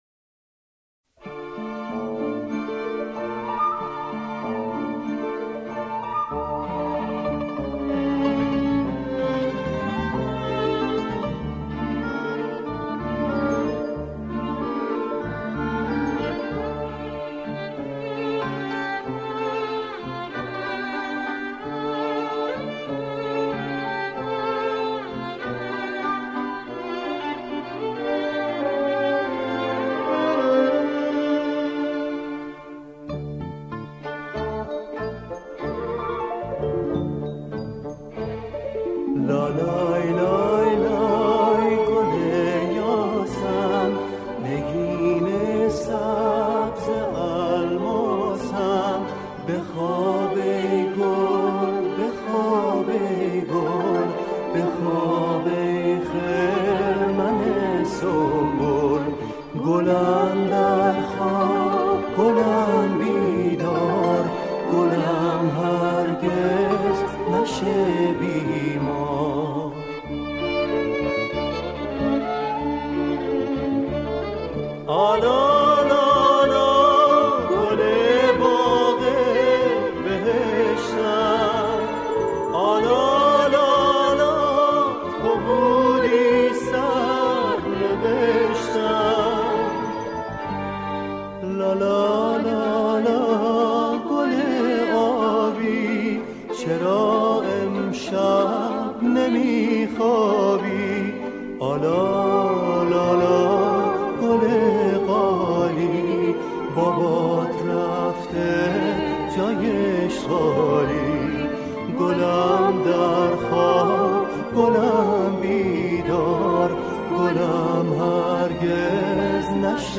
لالایی